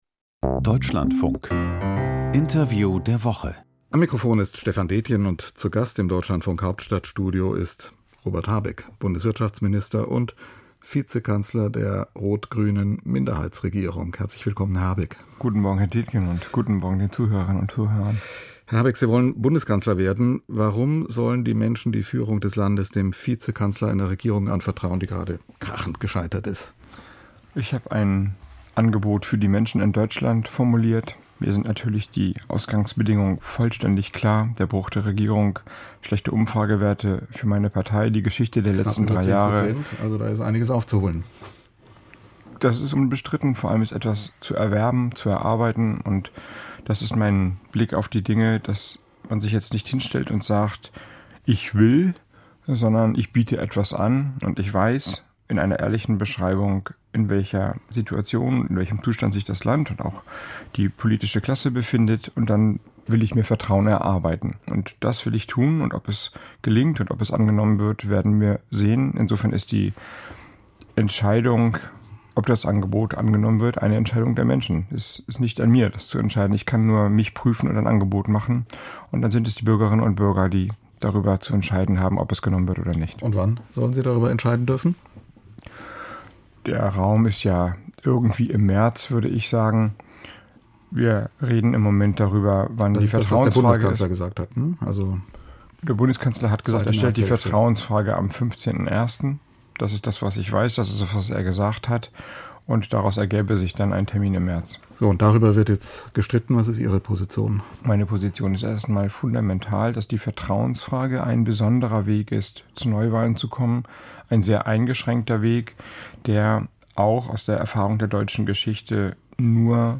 Nach dem Ampel-Aus: Robert Habeck (Grüne) im Interview